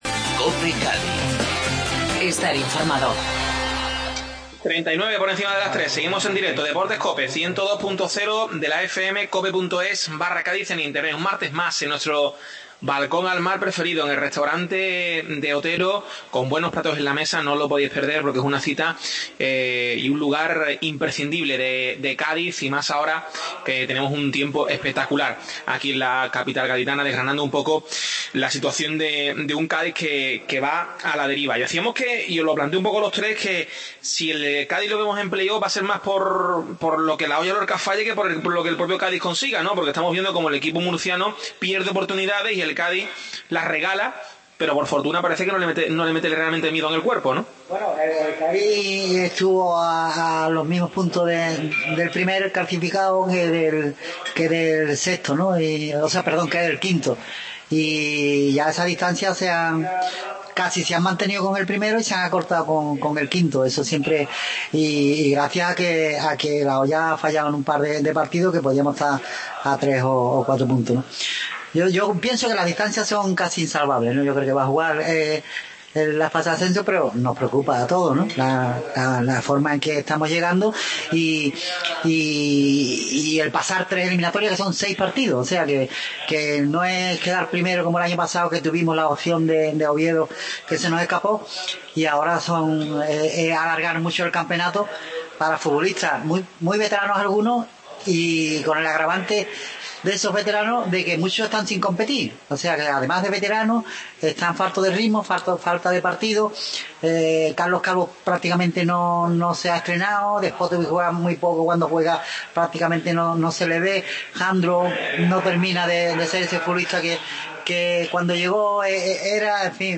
AUDIO: Segunda parte de la tertulia desde el Restaurante De Otero